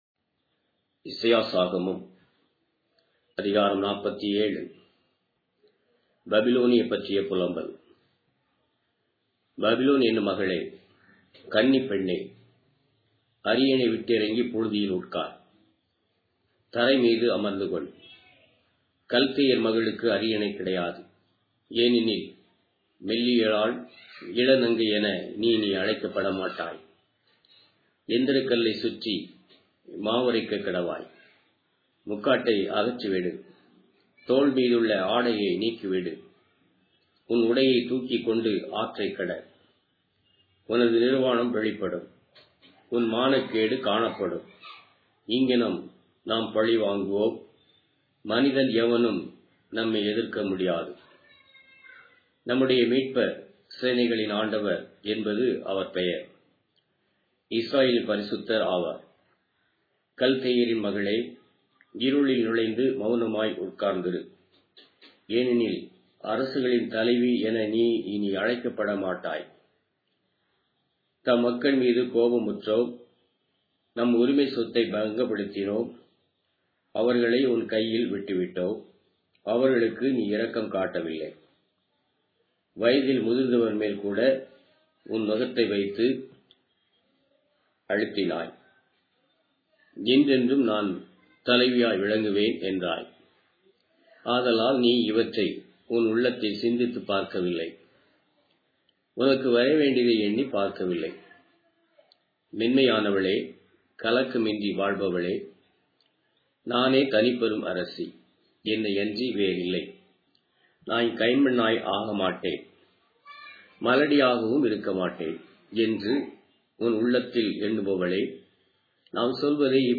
Tamil Audio Bible - Isaiah 18 in Rcta bible version